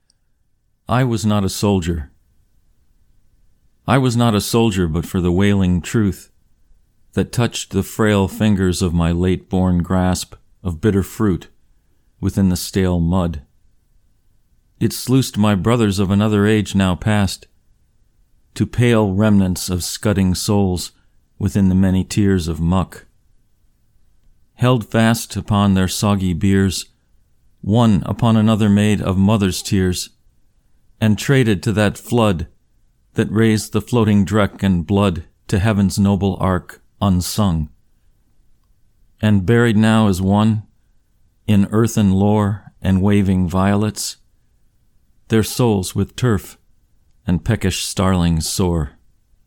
I Was Not A Soldier (Recitation)